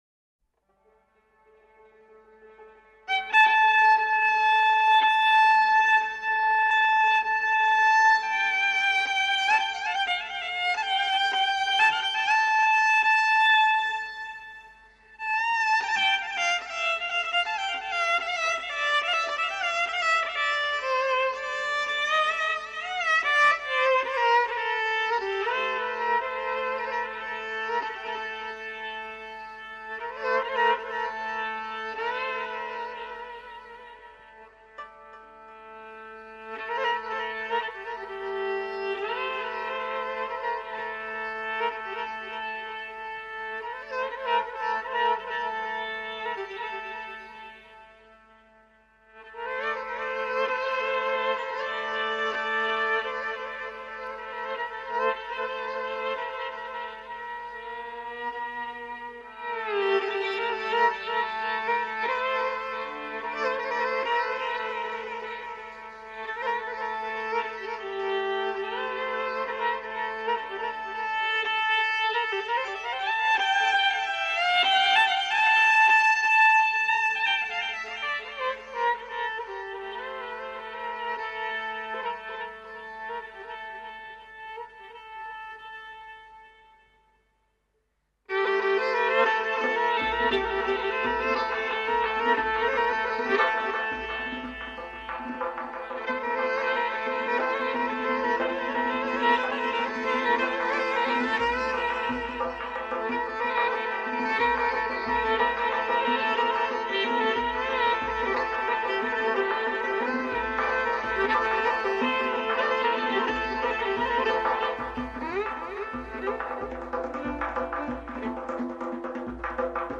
traditional Persian instrumentals